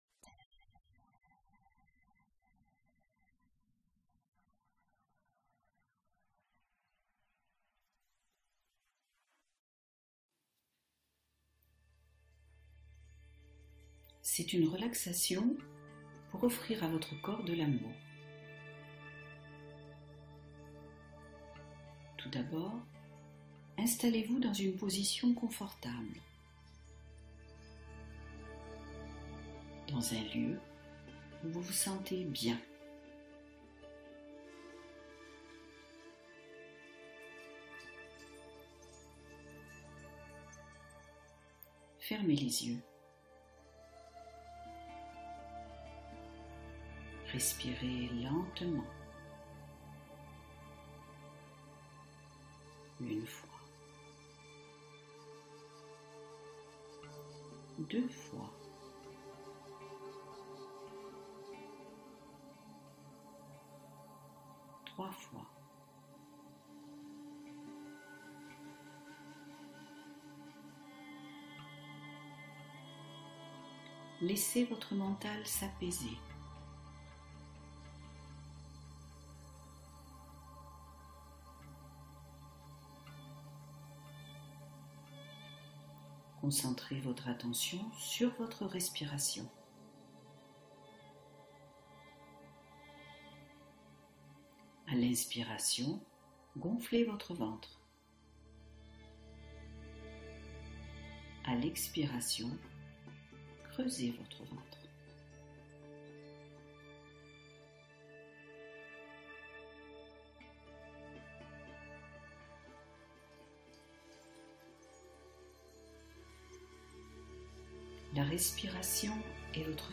Un moment de réconfort : méditation guidée pour nourrir l’âme